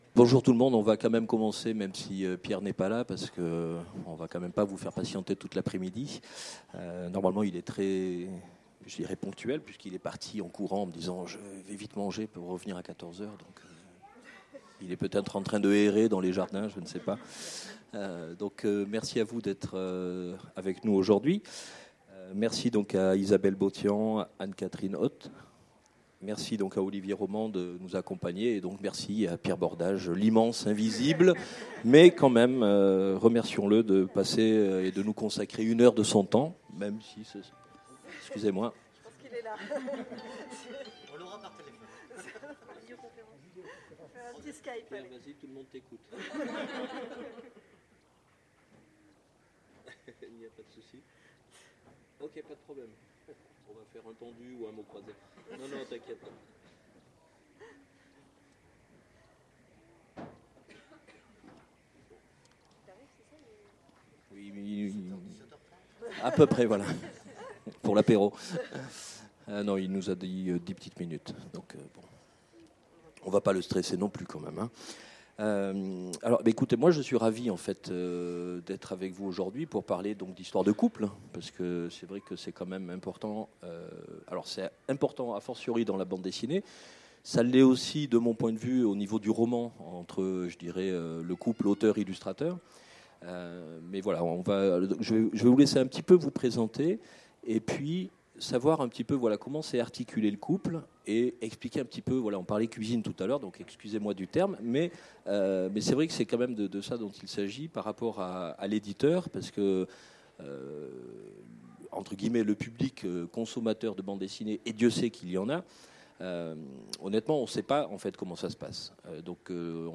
Imaginales 2017 : Conférence Scénaristes et dessinateurs… Deux auteurs pour une BD